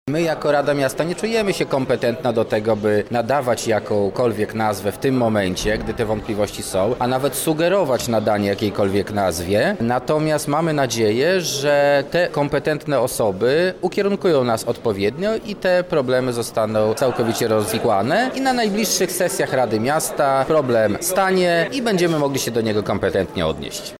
• mówi Marcin Nowak, Radny Miasta Lublin.